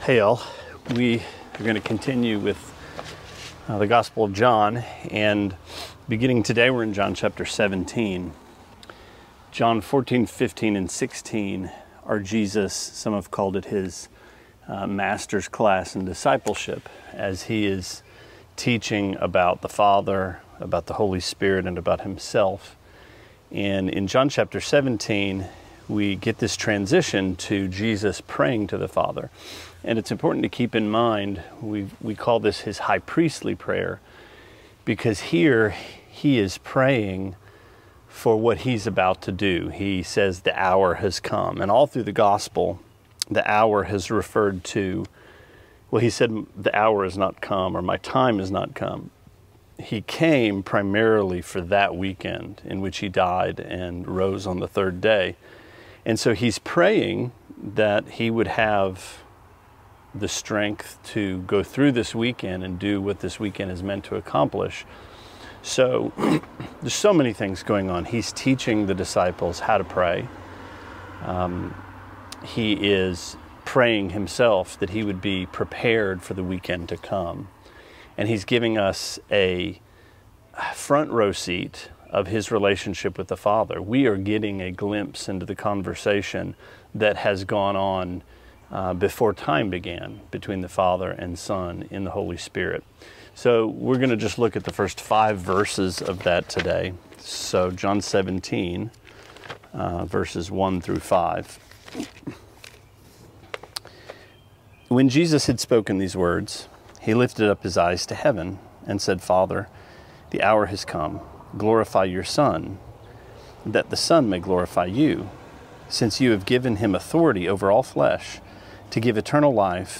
Sermonette 5/30: John 17:1-5: Eternal Life